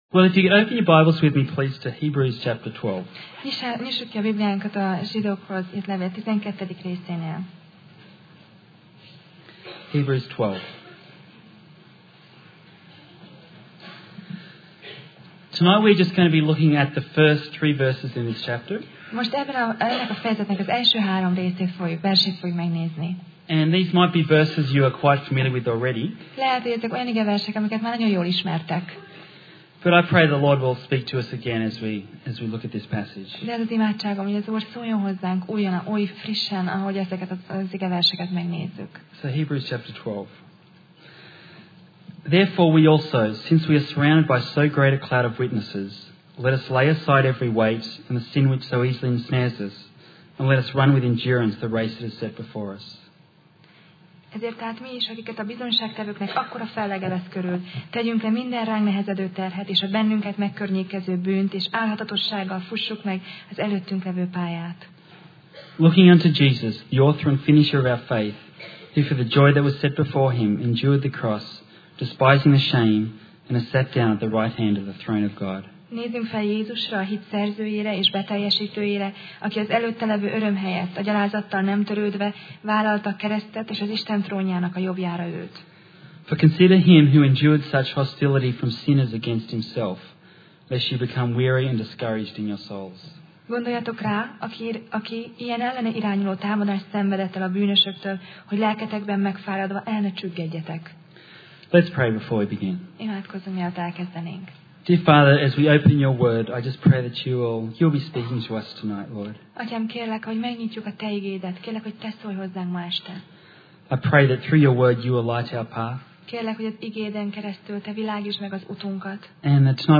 Sorozat: Tematikus tanítás Passage: Zsidók (Hebrews) 12:1-3 Alkalom: Szerda Este